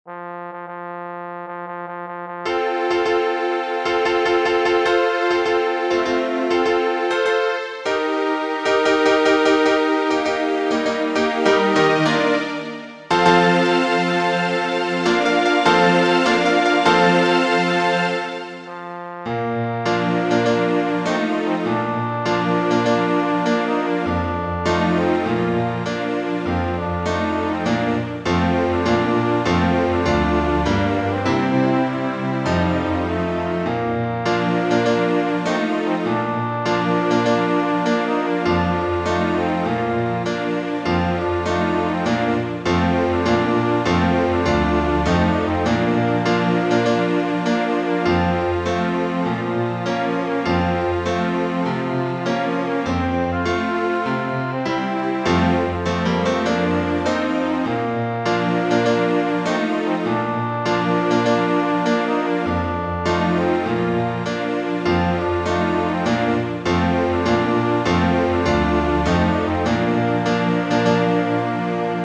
大行進は力強く印象的な曲で、多くの映画やテレビ番組で使用されてきました。
クラシック